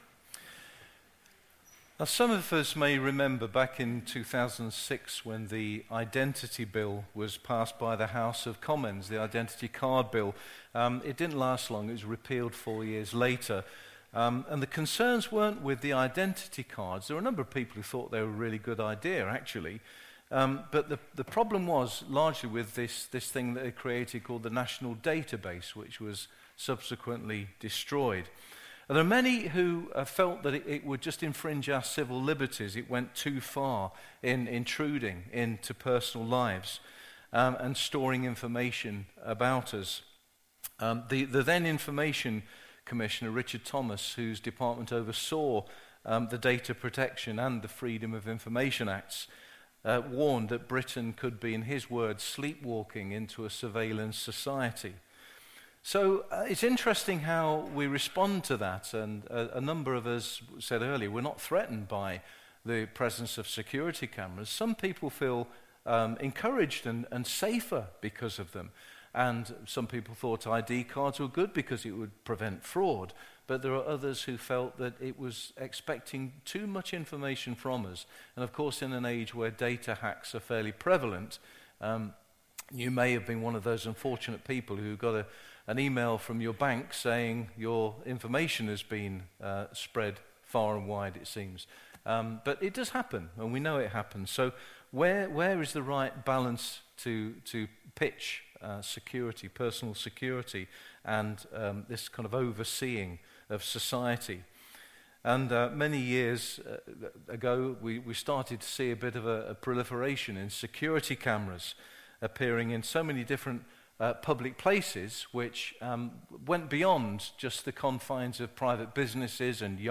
An audio version of the sermon is also available.
06-16-sermon.mp3